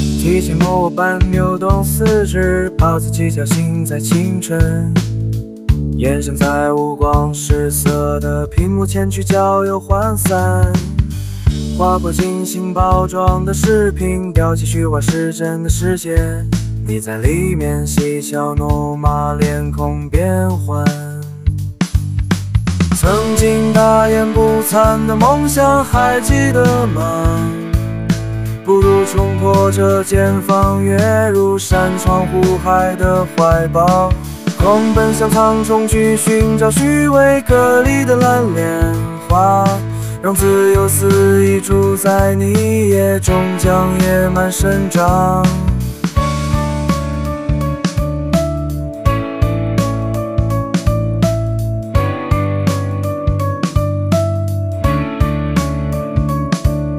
AI音乐生成示例：